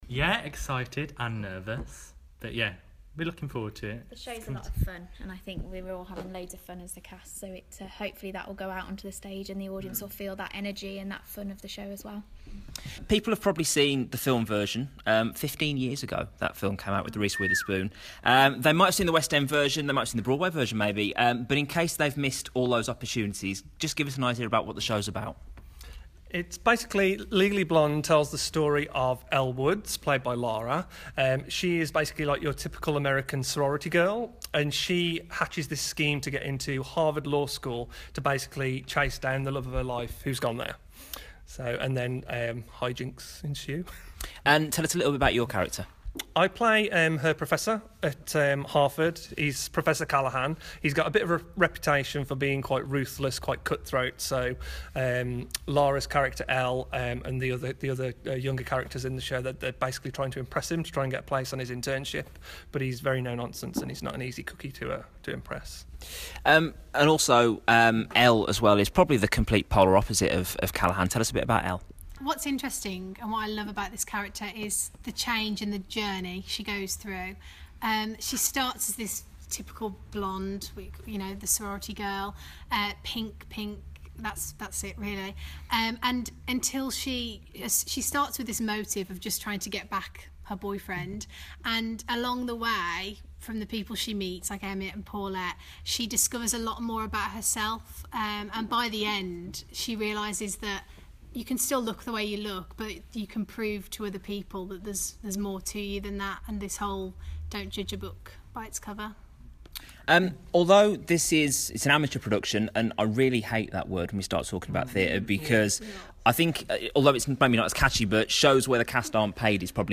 Stourbridge Operatic Society are bringing the musical theatre gem, Legally Blonde, to the stage in Stourbridge for one week only. I chatted to the cast to see if they were excited to be heading towards opening night?